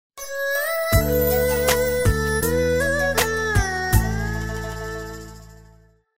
Message tone 06.mp3